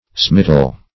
Smittle \Smit"tle\ (-t'l), v. t. [Freq. fr. OE. smitten to